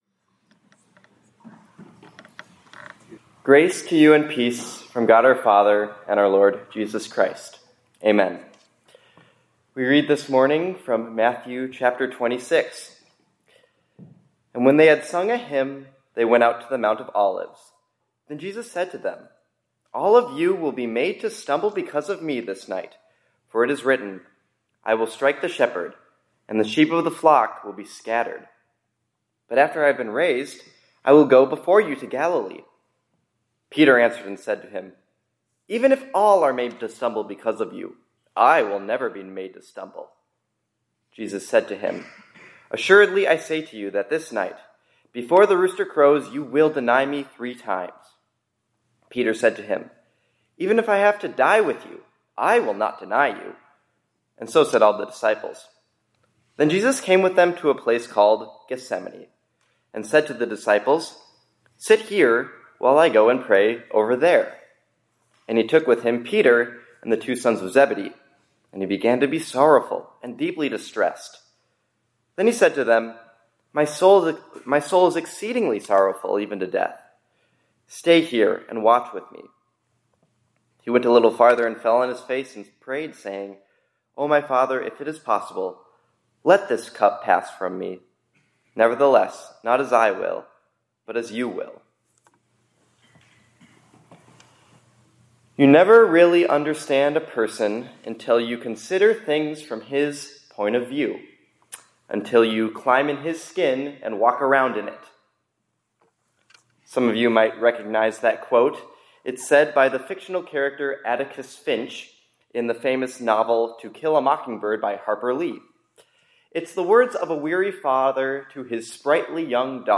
2026-03-30 ILC Chapel — Walk Around With Jesus